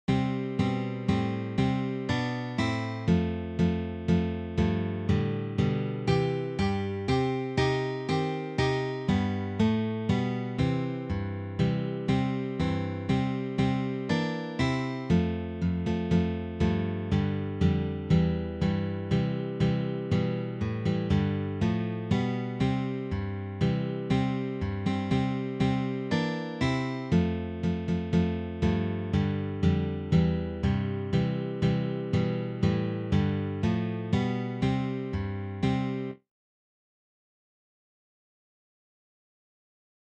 Ensemble Series
for three guitars